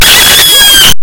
File Name: Alarm-Rooster.mp3